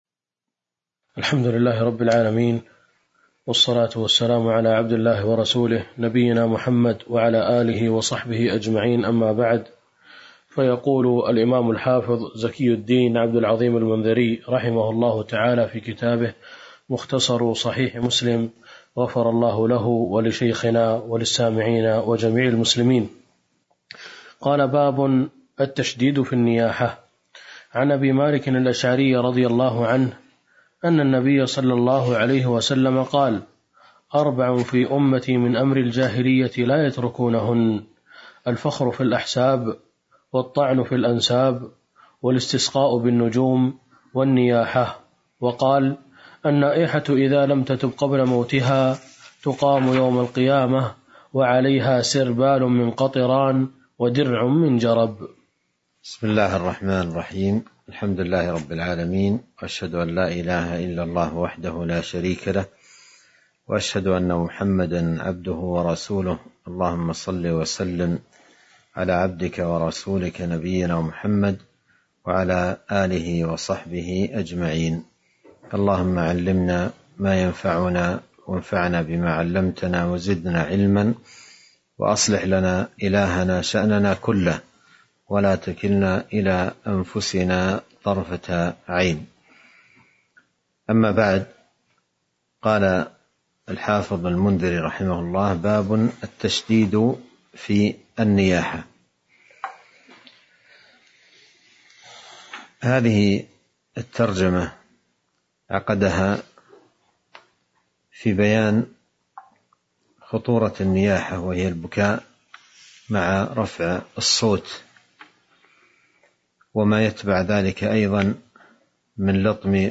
تاريخ النشر ٢٩ جمادى الآخرة ١٤٤٢ هـ المكان: المسجد النبوي الشيخ